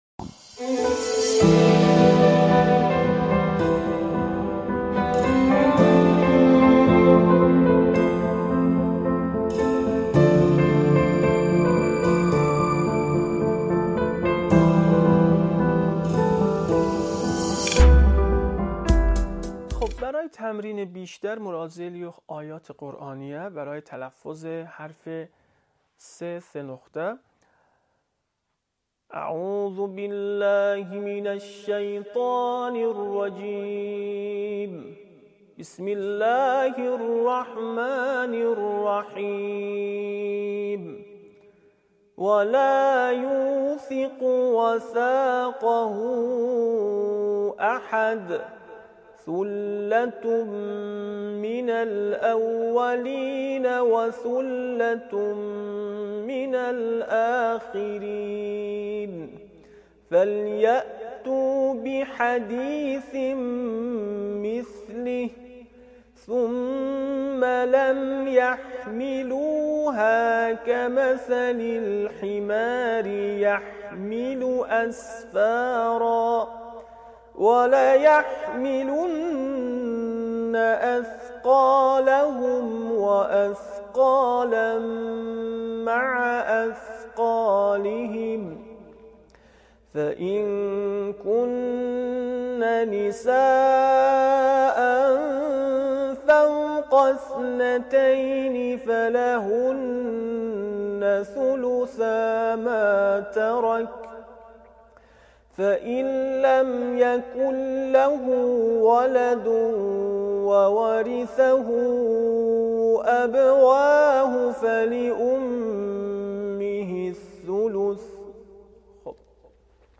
صوت | آموزش مجازی قرآن به زبان ترکی